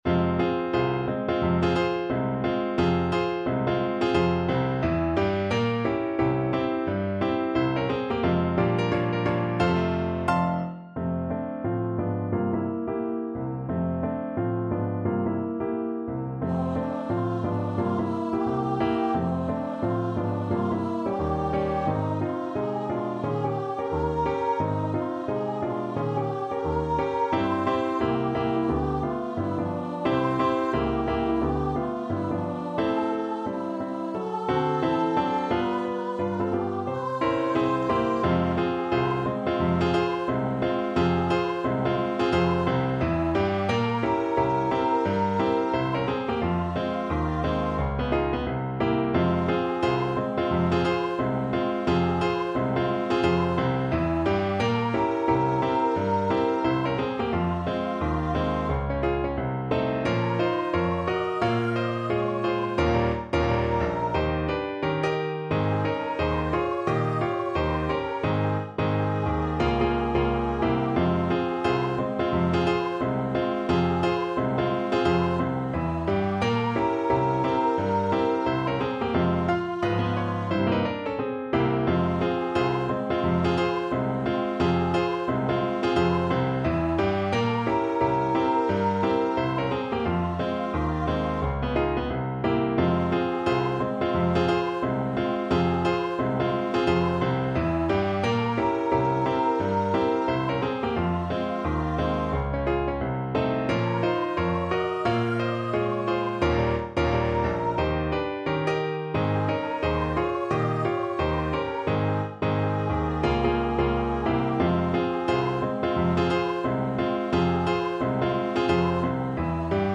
~ = 176 Moderato
C5-F6
Jazz (View more Jazz Voice Music)
Rock and pop (View more Rock and pop Voice Music)